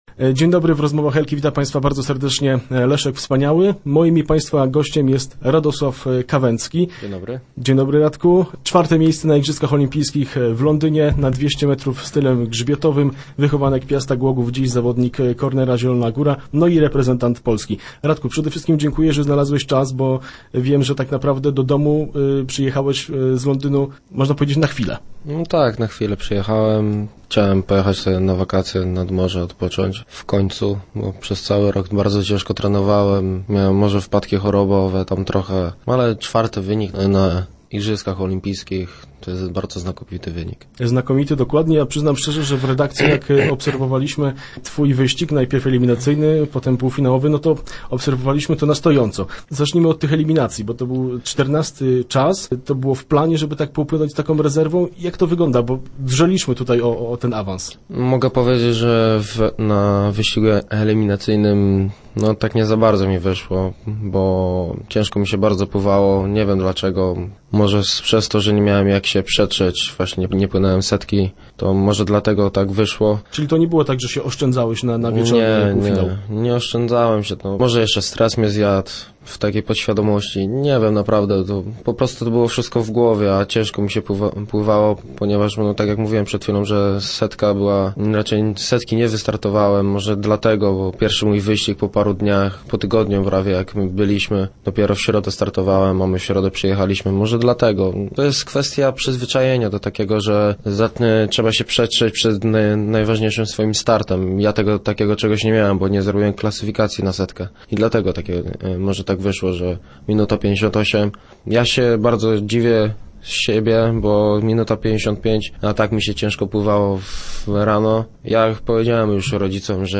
0807_kawcki_do_rozmw.jpgNiemal prosto z Londynu zameldował się w radiowym studiu. Czwarty grzbiecista Igrzysk Olimpijskich na 200m Radosław Kawęcki był gościem wtorkowych Rozmów Elki.
Zmęczony ciężkim sezonem oraz powrotem z Anglii, ale jednocześnie uśmiechnięty po znakomitym występie na IO.